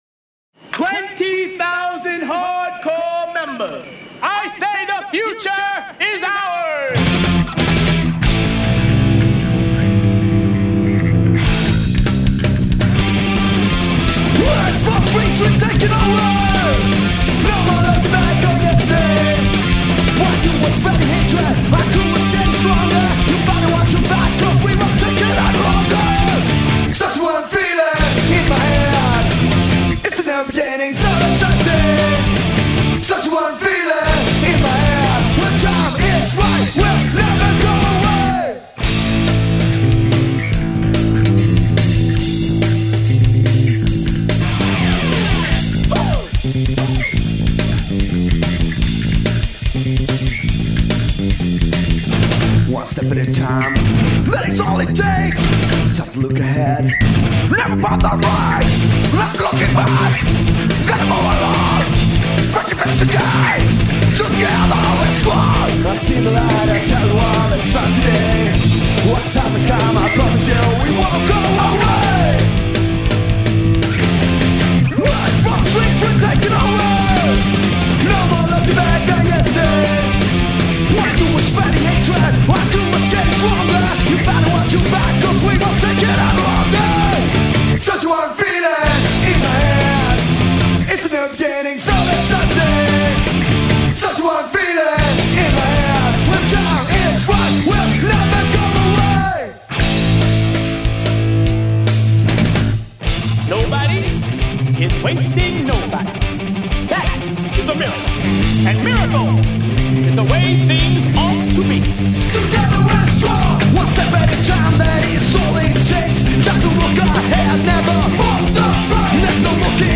abgefahrenen HC-Punk